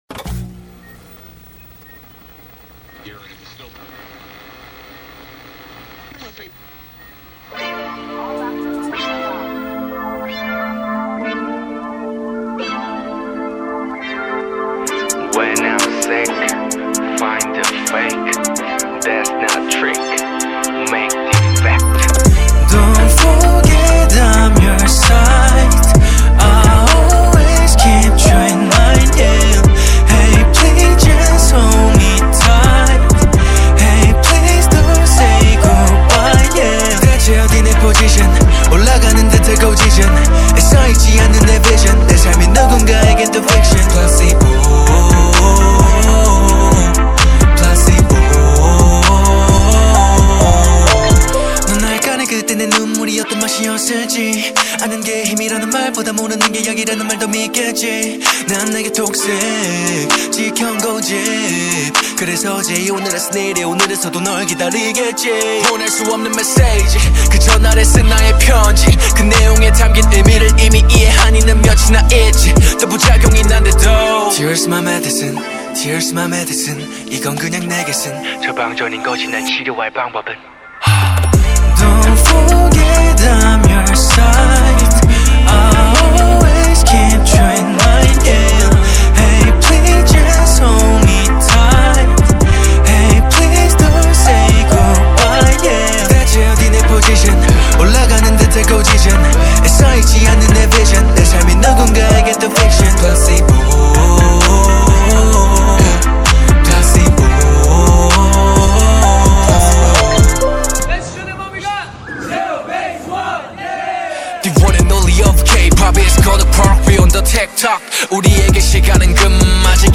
KPop